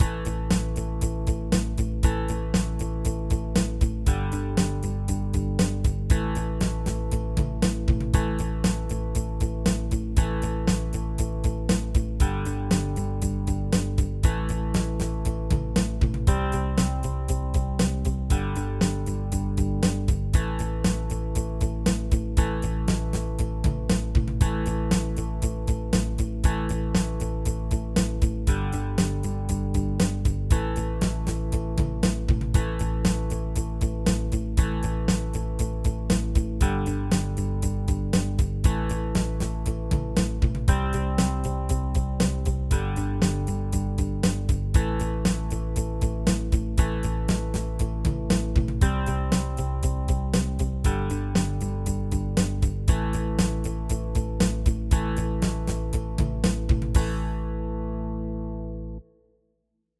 Ackord: består av minst 3 toner som klingar samtidigt.
Bakgrund 1 med gitarr, trummor och bas
Bakgrund-1-Rock_El-git-bas-trumset.mp3